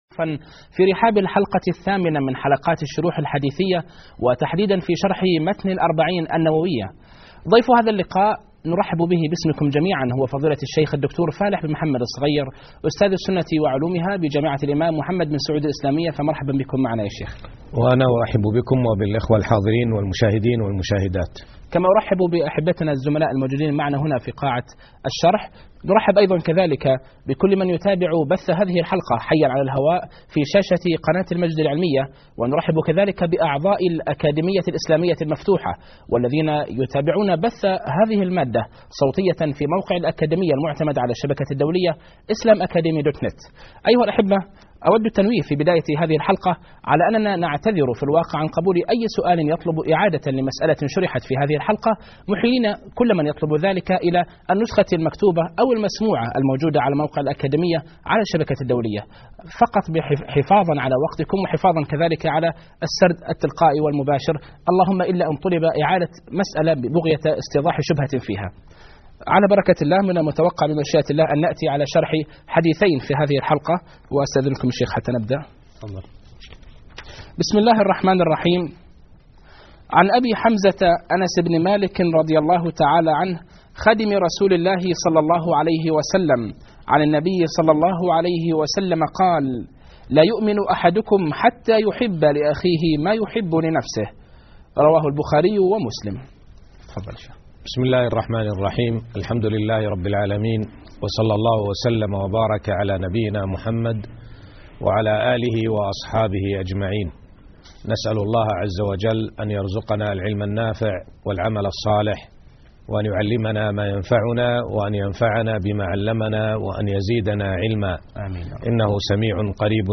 الدرس الثامن _ الحديث الثالث عشر _ الرابع عشر